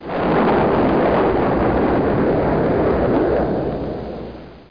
jet.mp3